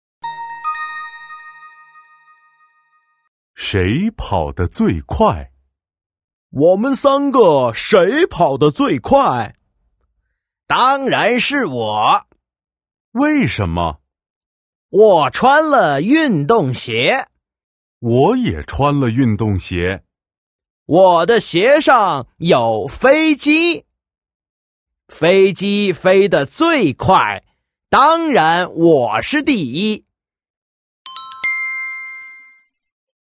Đọc truyện